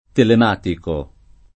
telematico [ telem # tiko ]